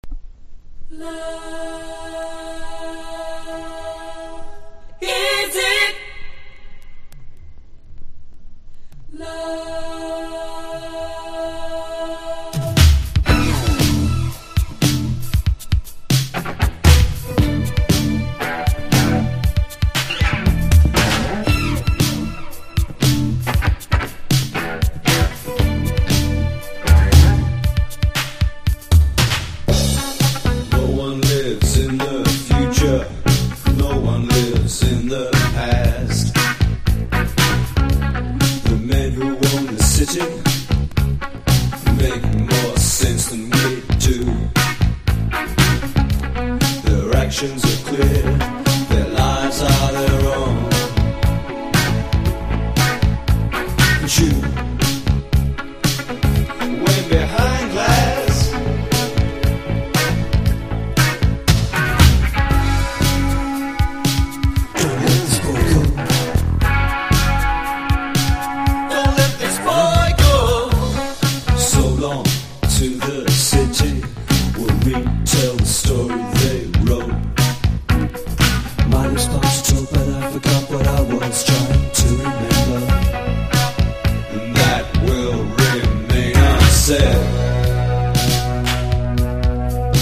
ヒリヒリ感漂う元祖ディスコ･パンク！